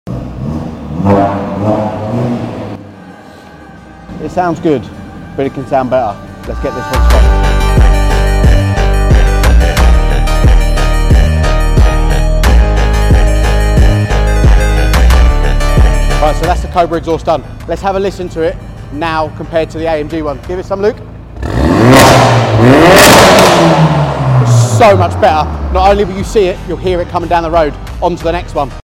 🔥 Factory AMG Exhaust ➡ sound effects free download
🔥 This A-Class AMG just got a serious sound and performance boost! 🚀💨 Watch as we swap out the stock AMG exhaust for a Cobra system—louder, deeper, and way more aggressive!